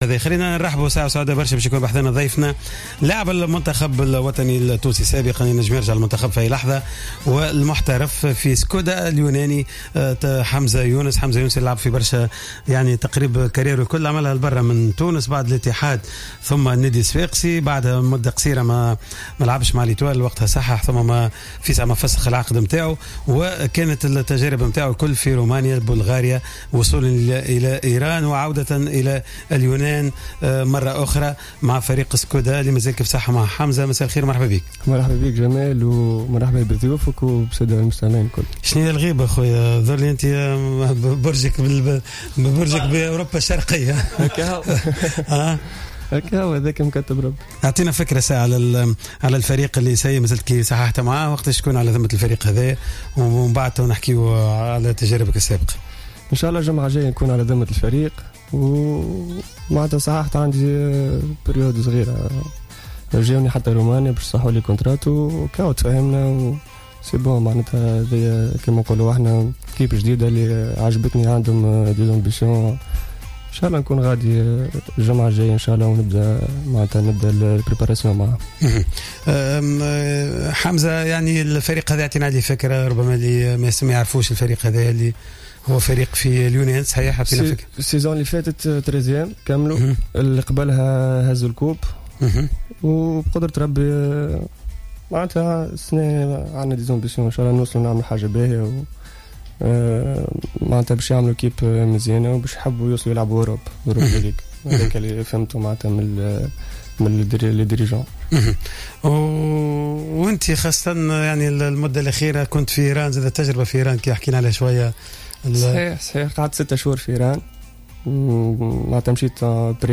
تحدث اللاعب حمزة يونس ضيف حصة "يورو 2016" المنضم حديثا إلى فريق سكودا اليوناني عن مختلف تجاربه التي خاضها في كل من رومانيا و بلغاريا مشيرا أنه إستطاع التأقلم سريعا مع البطولات المذكورة و تمكن من تهديف في أكثر من مناسبة مما جعله محل صراع بين الأندية الكبرى في رومانيا.